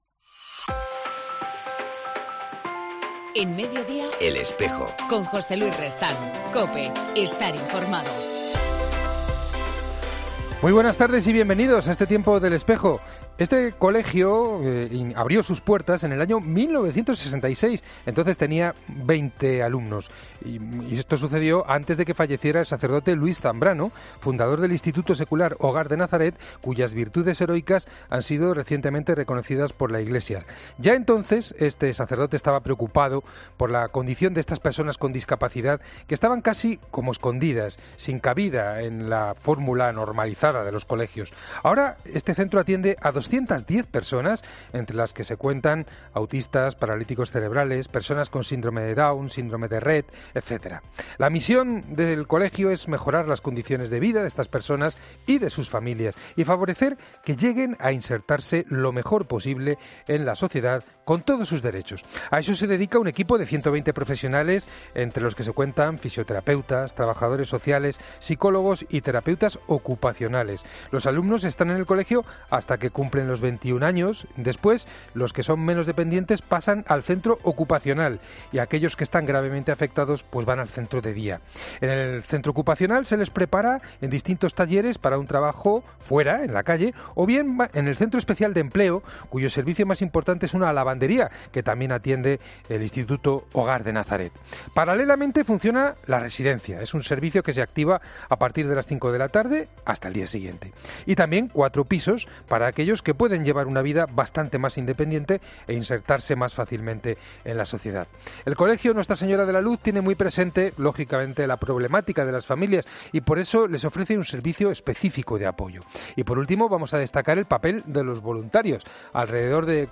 entrvista